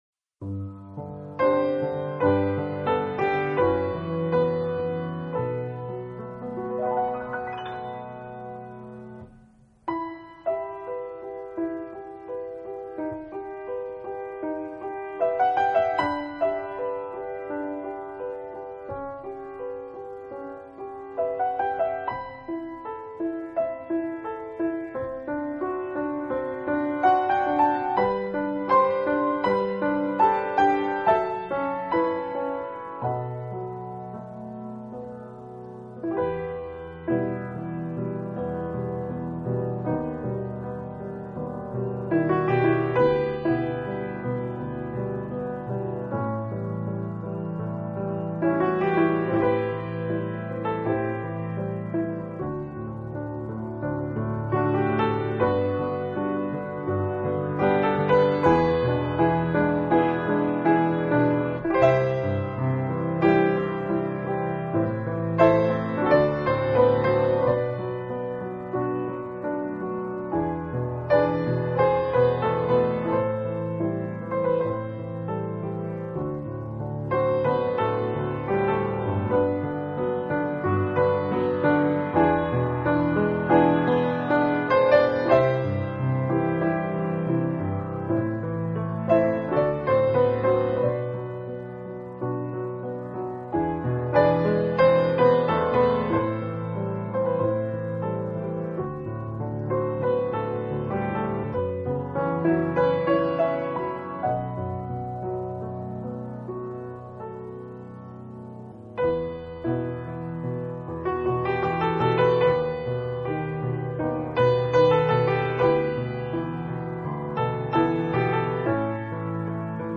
本套CD全部钢琴演奏，